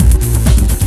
TECHNO125BPM 14.wav